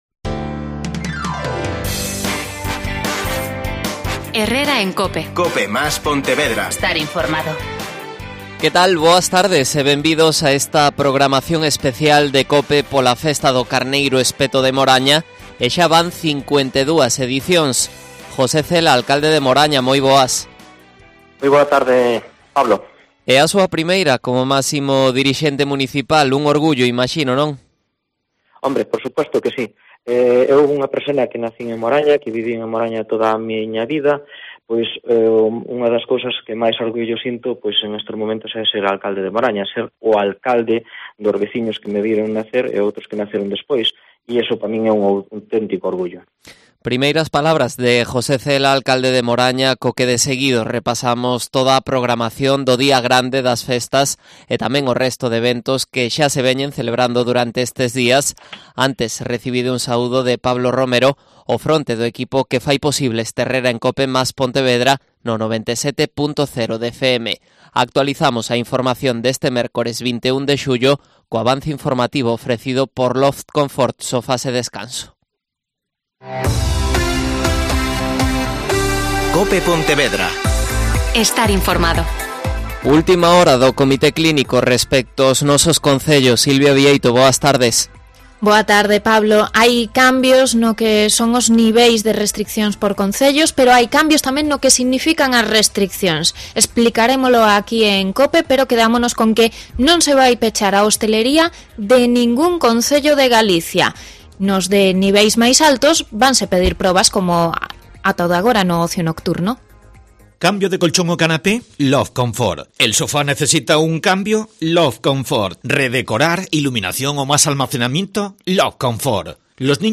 Redacción digital Madrid - Publicado el 21 jul 2021, 13:05 - Actualizado 17 mar 2023, 22:39 1 min lectura Descargar Facebook Twitter Whatsapp Telegram Enviar por email Copiar enlace Programa Especial "Carneiro ao Espeto 2021" Concello de Moraña. José Cela. Alcalde de Moraña.